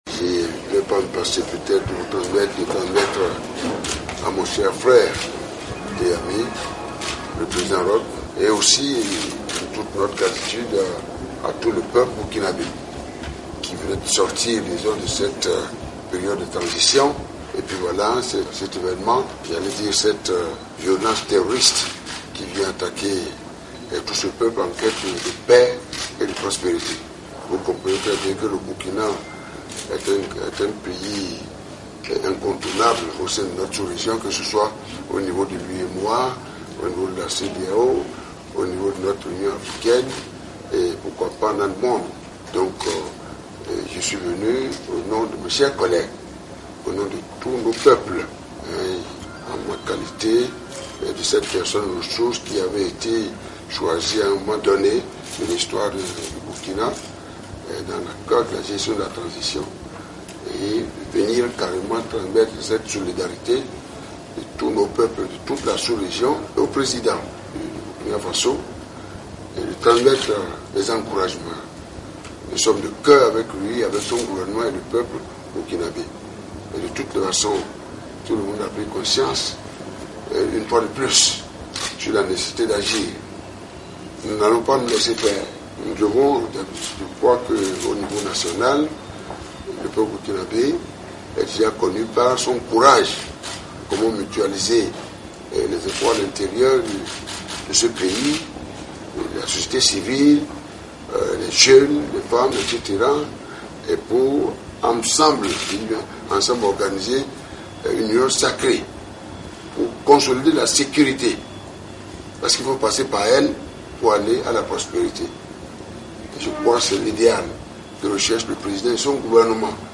Extrait du discours du président béninois Thomas Boni Yayi à son arrivée à Ouagadougou